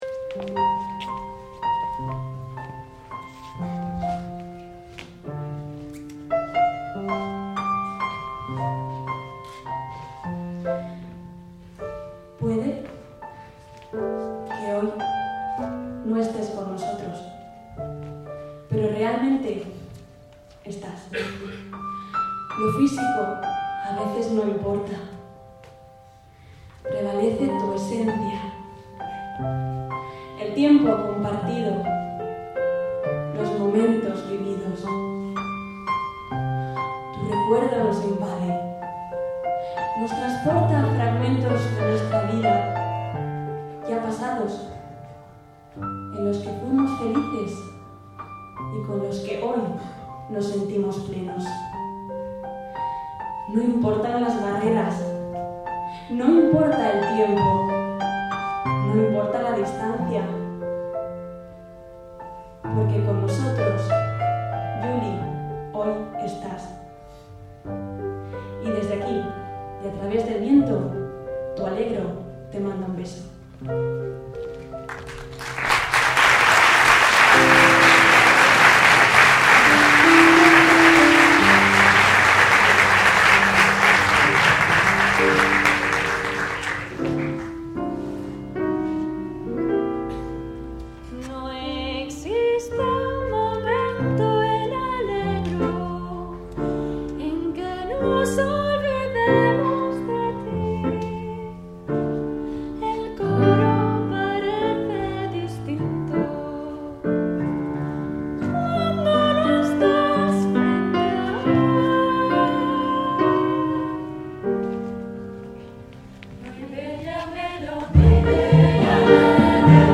Ellos, entonces, le dedicaron desde el escenario una emocionante pieza: